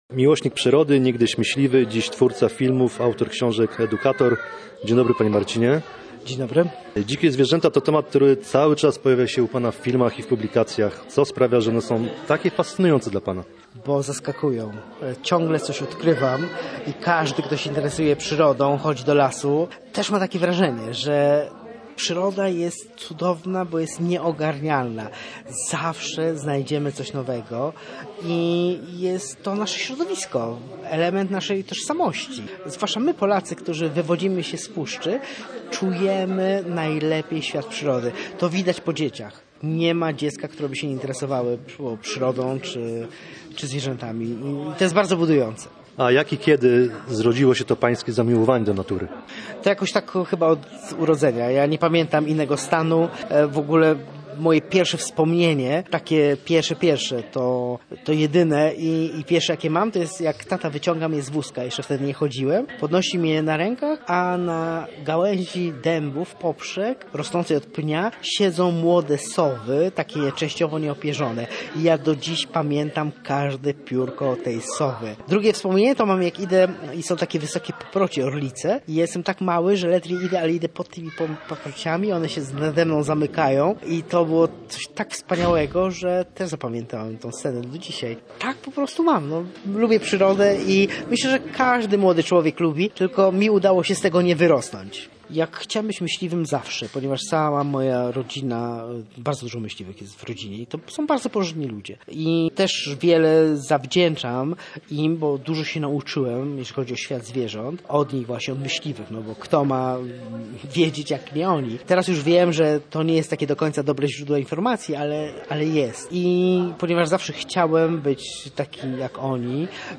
Plotki o zwierzętach w słupskiej bibliotece